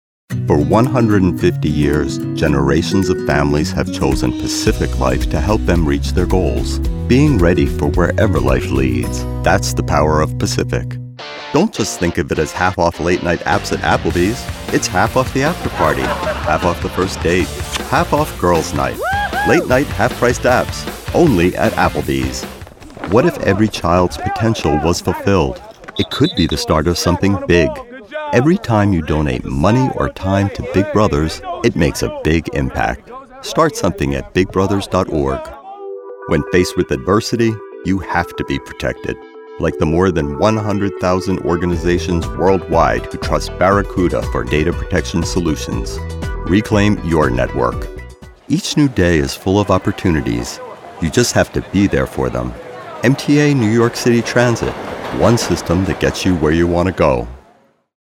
Adult (30-50) | Older Sound (50+)
Radio & TV Commercial Voice